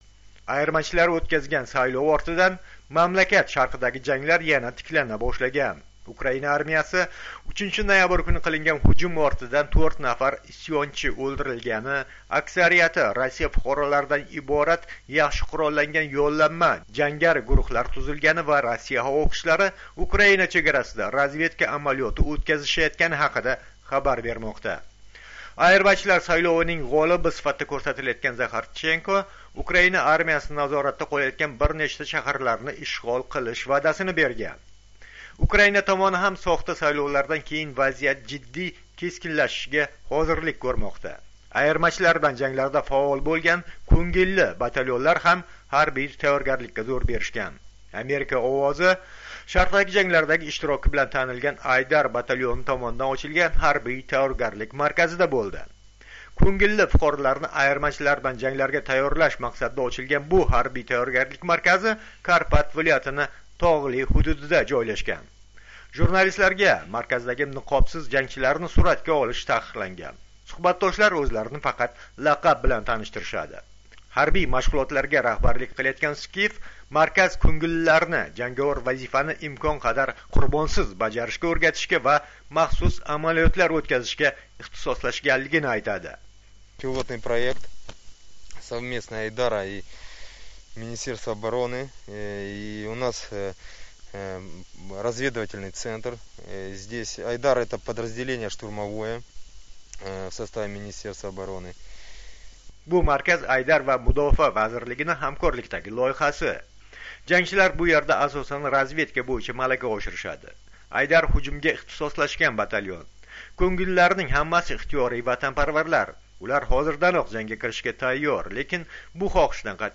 Ukrainada ko'ngilli jangchilar mashq markazidan reportaj